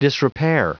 Prononciation du mot disrepair en anglais (fichier audio)